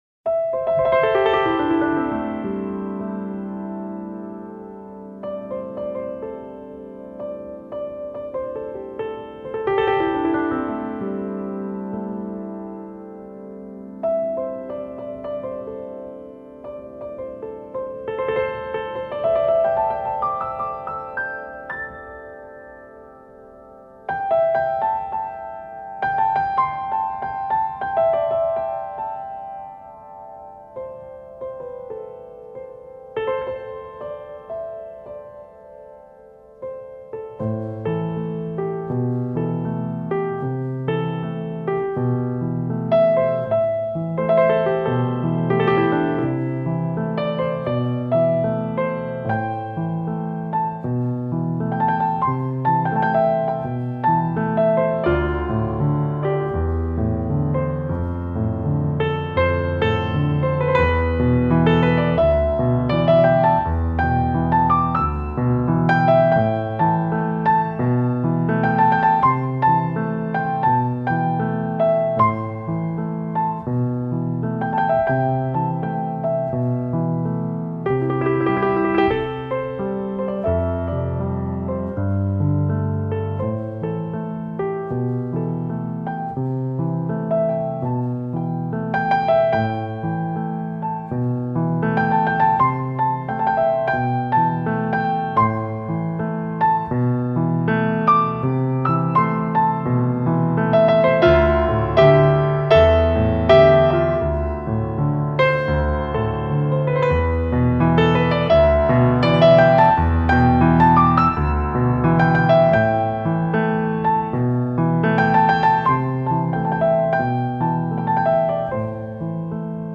类型：钢琴演奏
轻快的琶音和弦，展现落叶缤纷的唯美动感